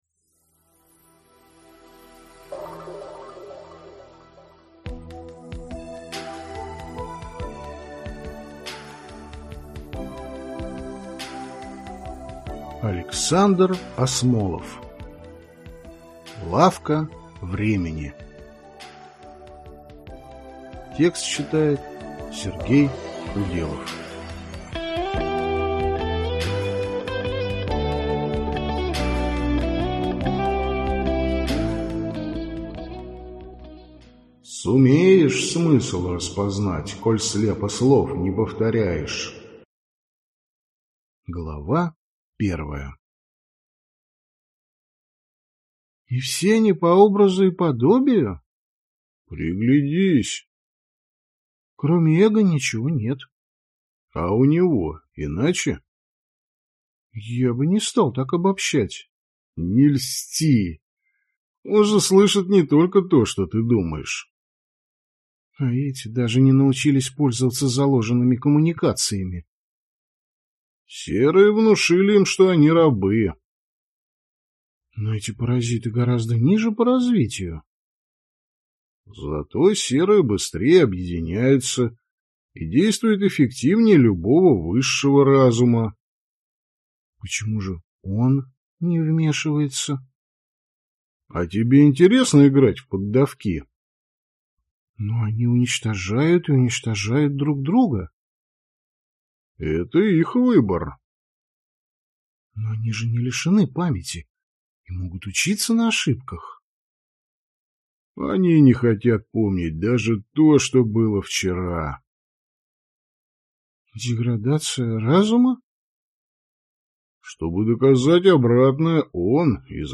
Аудиокнига Лавка времени | Библиотека аудиокниг